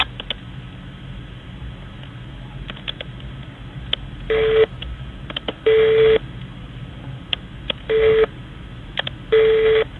Старый модем